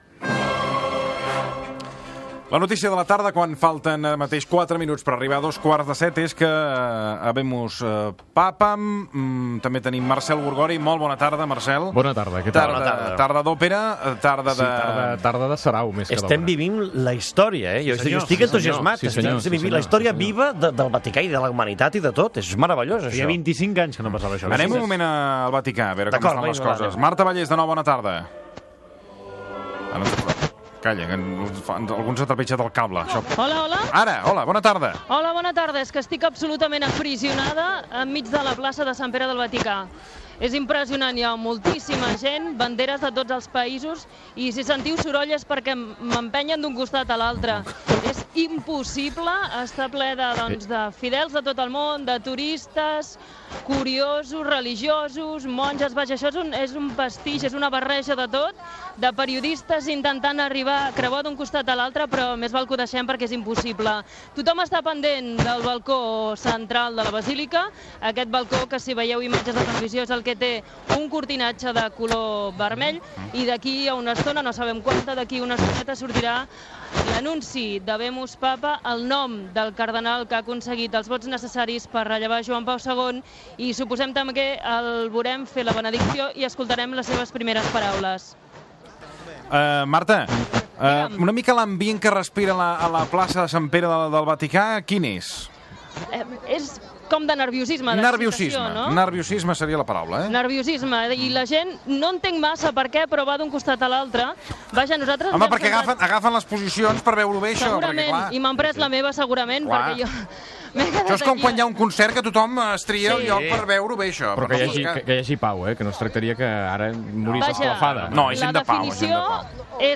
Hora, secció d'òpera amb connexions amb la Ciutat del Vaticà on es proclama papa a Joseph Ratzinger com a Benet XVI